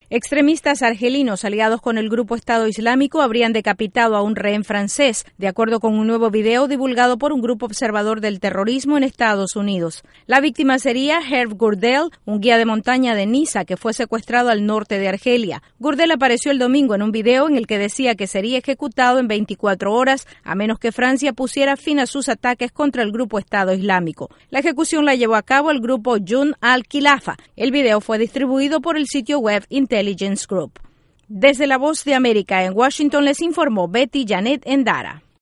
Decapitan a rehén francés. Desde la Voz de América en Washington